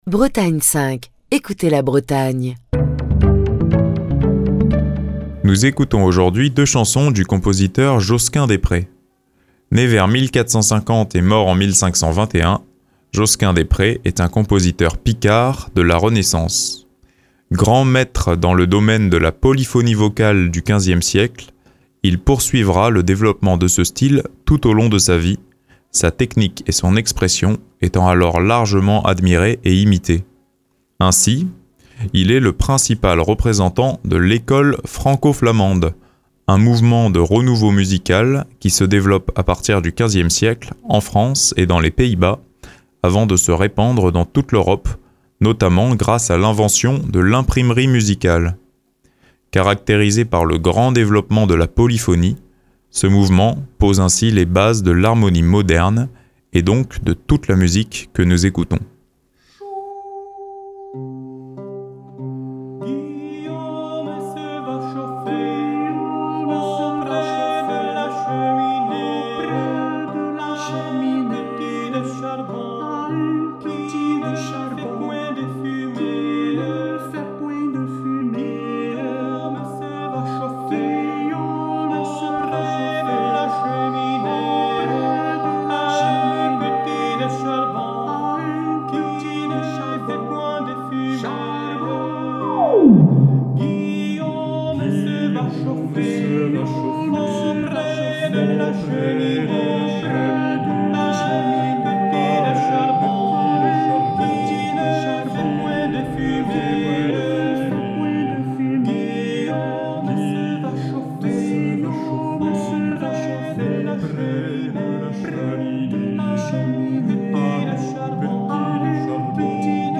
Je vous propose d'écouter ce matin "Guillaume se va chauffer" et "Je ris", deux pièces du compositeur Josquin des Prez, interprétées par l’Ensemble Thélème. Cet ensemble réunit des musiciens spécialisés dans les instruments d’époque, tels que la voix, le luth et un large panel d'instruments anciens, pour interpréter le riche répertoire polyphonique de la Renaissance.